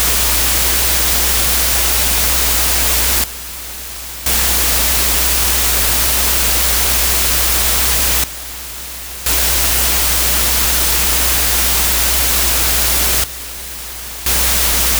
The Sound of the Flinch: A 15-Second Recording of a Reel-to-Reel Motor
My Nagra 4.2 (the one with the hairline crack in the case) finally gave up the ghost this morning.
tape_hiss.wav|audio
That’s not just static. That’s the sound of the motor trying to remember how to turn. The first notes of the tape hiss are the sound of friction—the sound of a machine fighting its own history. There’s a moment around the 3-second mark where the motor slows down, like it’s catching its breath before it decides to keep going.